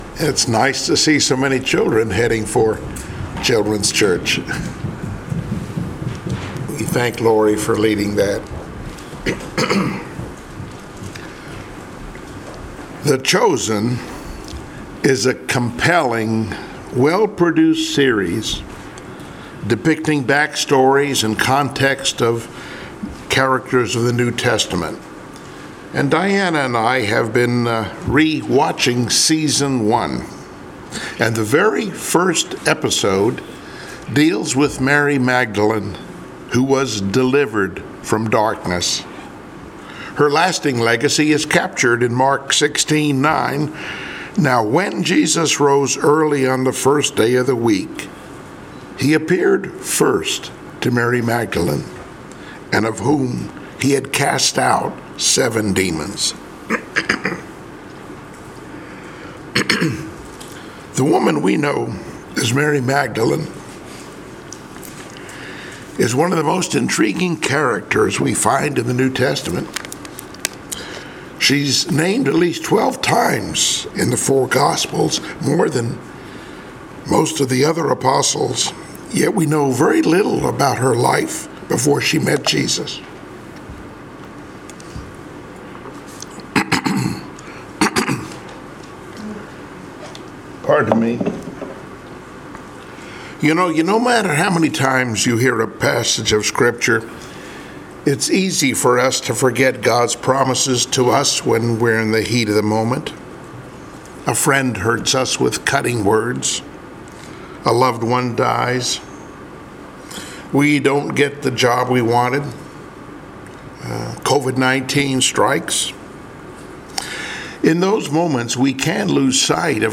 Passage: Joh 20:1-18 Service Type: Sunday Morning Worship